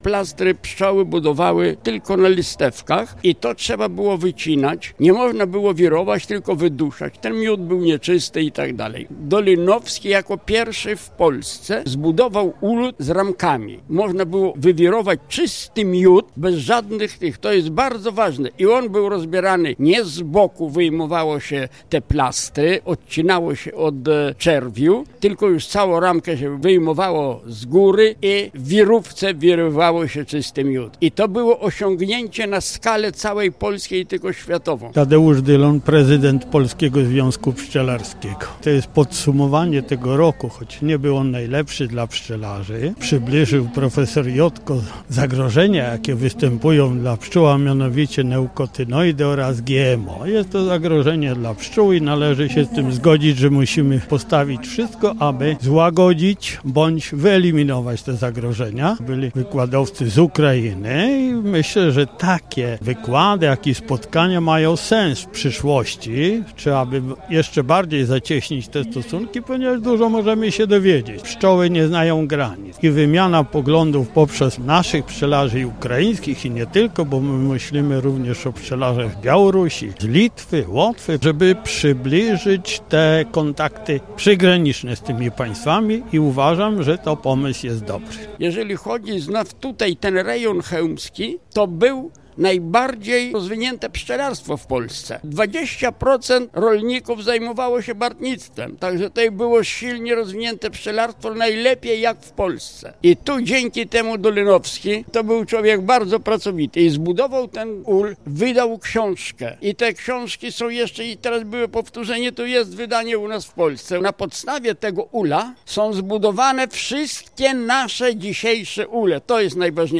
Pszczelarze świętowali w Hańsku, gdzie już po raz piętnasty odbył się Dzień Pszczelarza poświęcony pamięci ks. Jana Dolinowskiego, żyjącego w XIX wieku polskiego duchownego greckokatolickiego, pszczelarza, twórcy i konstruktora pierwszego polskiego ula ramowego.